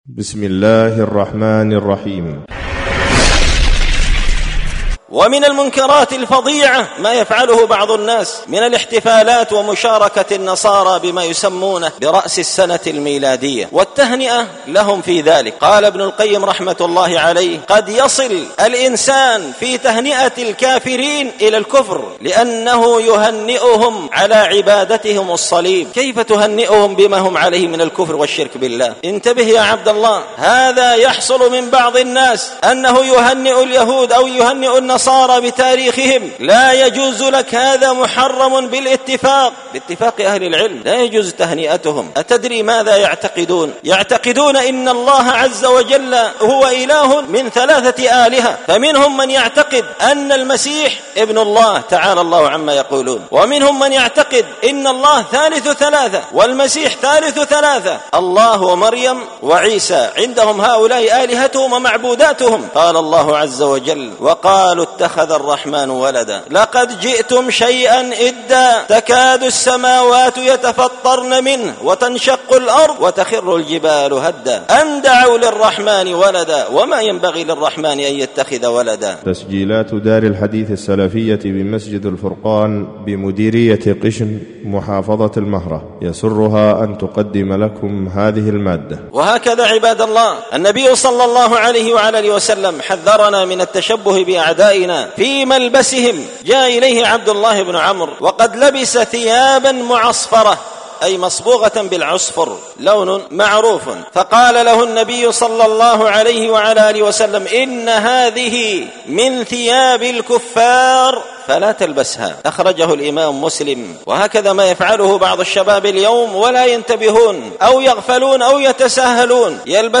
الجمعة 16 جمادى الآخرة 1445 هــــ | الخطب والمحاضرات والكلمات | شارك بتعليقك | 110 المشاهدات
ألقيت هذه الخطبة بدار الحديث السلفية بمسجد الفرقان قشن-المهرة-اليمن تحميل